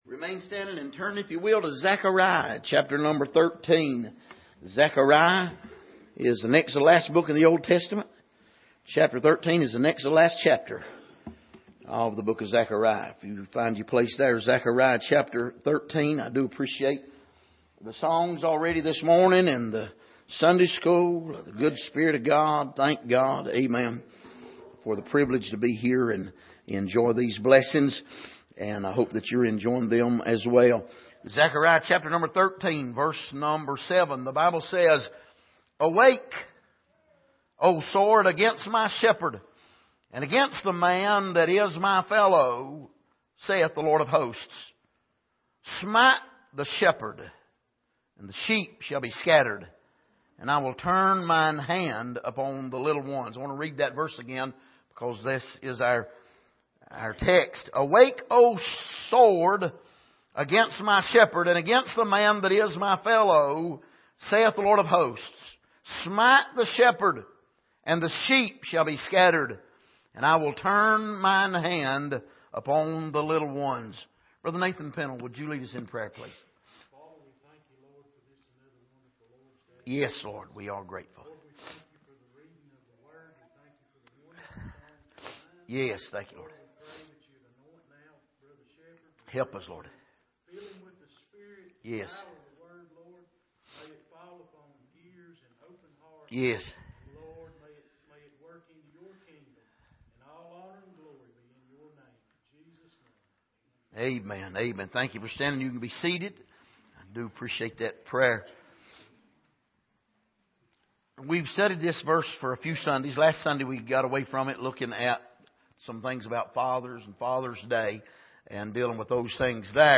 Passage: Zechariah 13:7 Service: Sunday Morning